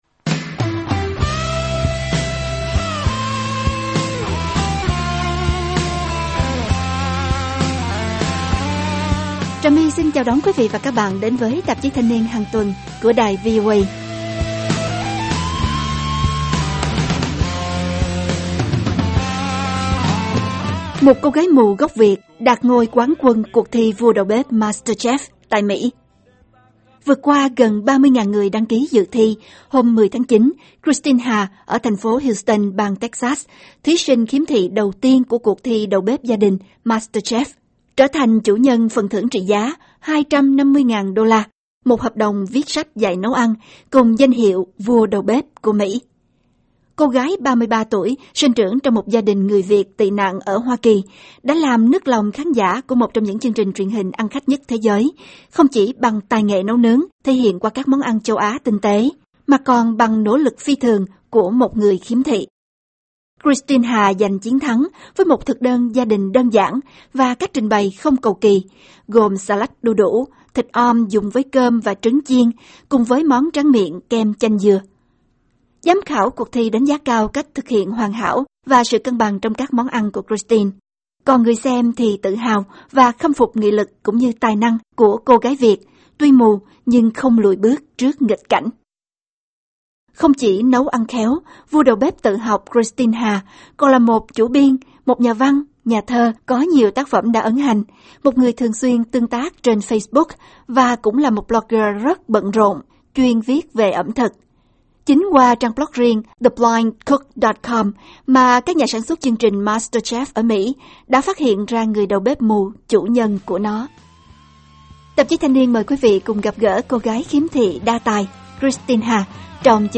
Phỏng vấn cô gái Việt lên ngôi Vua Đầu bếp Mỹ